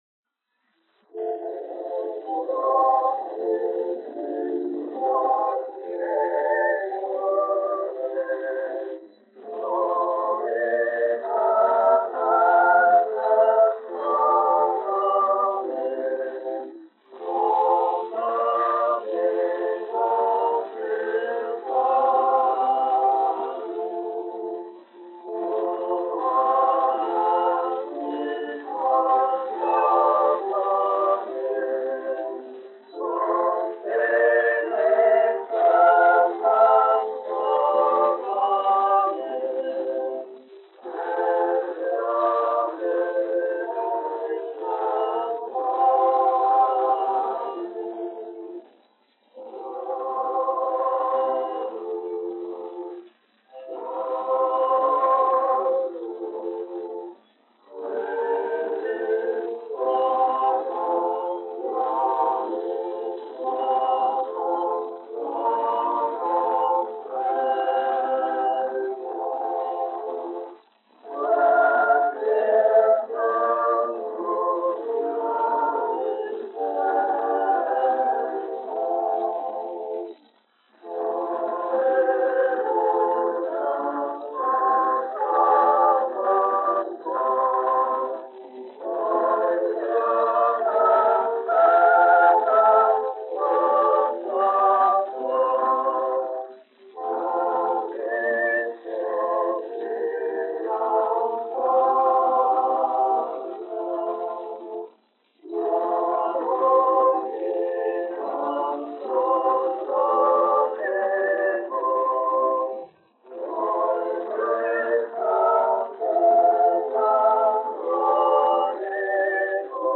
Rīgas Latviešu dziedāšanas biedrības jauktais koris, izpildītājs
1 skpl. : analogs, 78 apgr/min, mono ; 25 cm
Kori (jauktie)
Ziemassvētku mūzika
Latvijas vēsturiskie šellaka skaņuplašu ieraksti (Kolekcija)